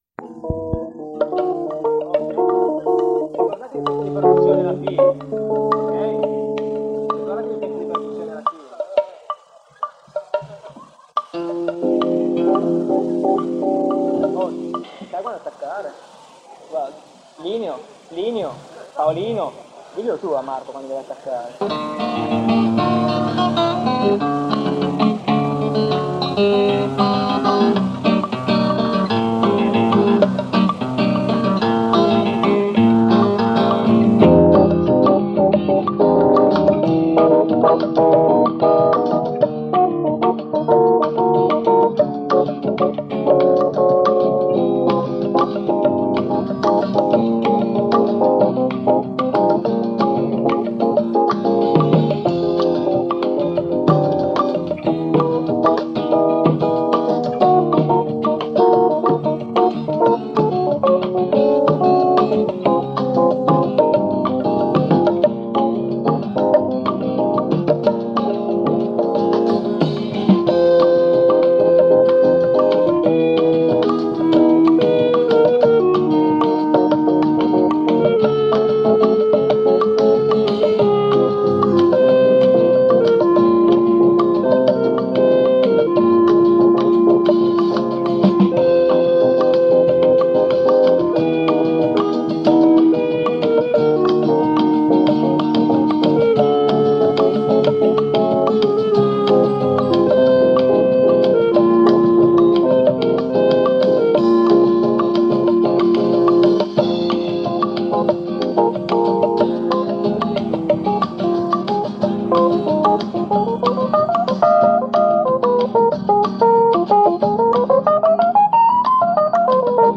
voce
percussioni